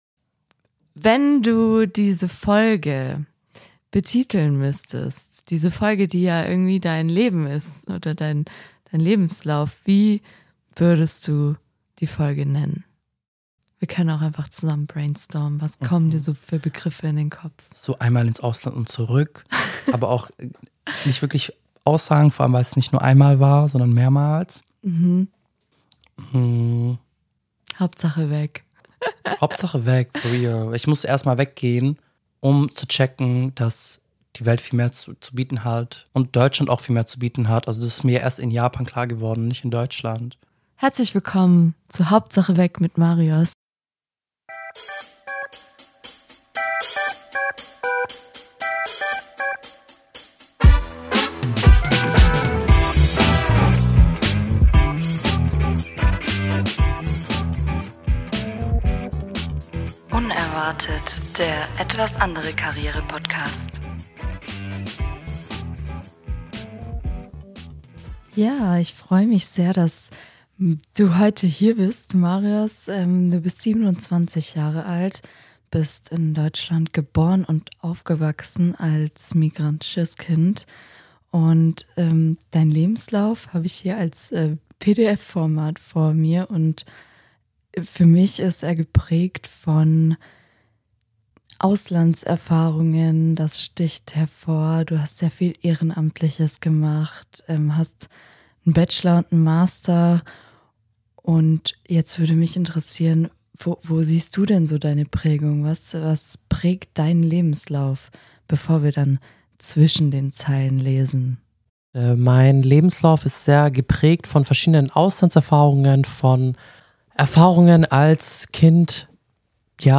Ein Gespräch über Identität, Selbstzweifel und die Kraft, seinen eigenen Weg zu gehen.